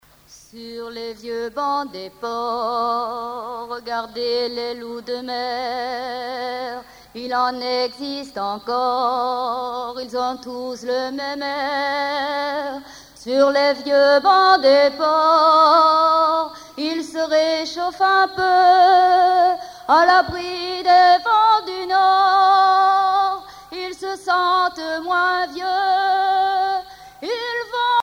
Genre strophique
Chansons de la soirée douarneniste 88
Pièce musicale inédite